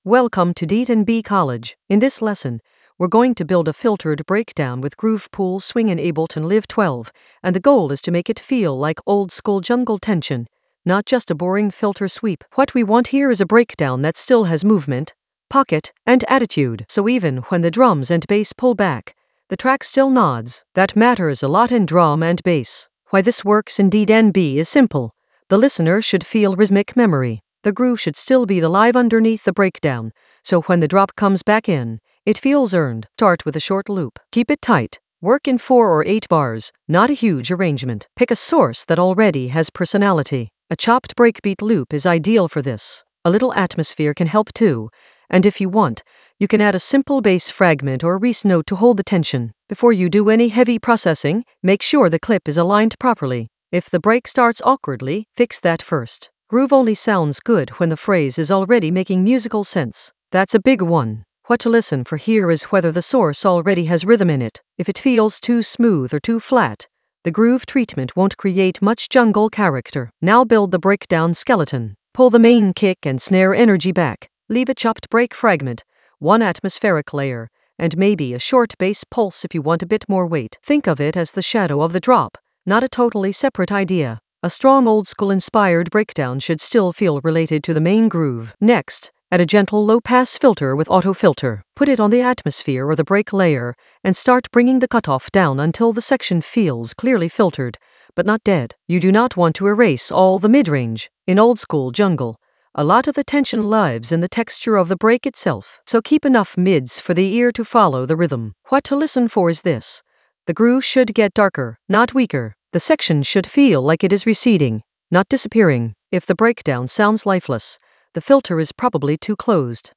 An AI-generated beginner Ableton lesson focused on Carve a filtered breakdown with groove pool tricks in Ableton Live 12 for jungle oldskool DnB vibes in the Atmospheres area of drum and bass production.
Narrated lesson audio
The voice track includes the tutorial plus extra teacher commentary.